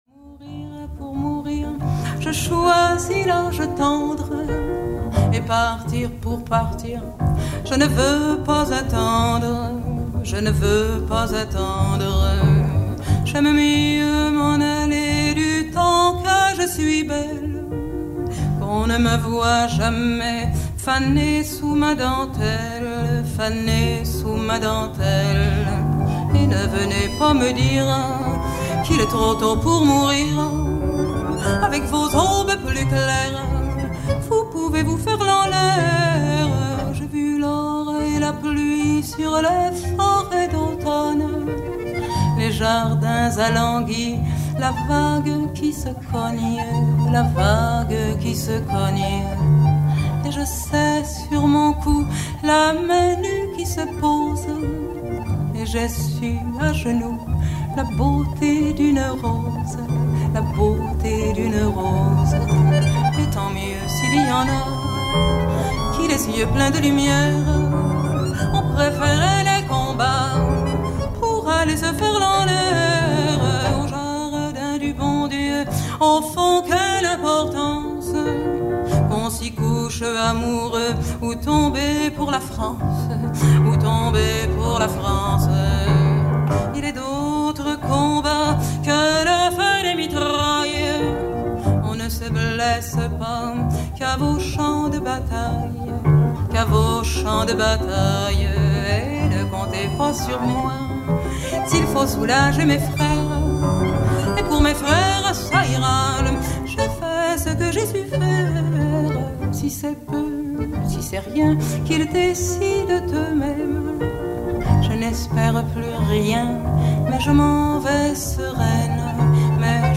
an extraordinary singer of songs about lost love